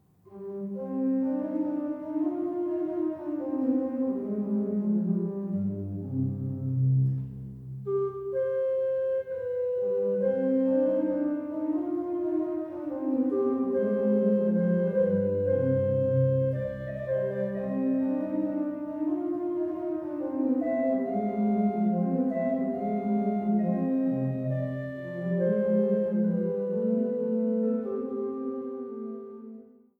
Glatter-Götz-/Rosales-Orgel im Remter des Magdeburger Domes